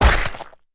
Punch Cartoon Sound Button - Free Download & Play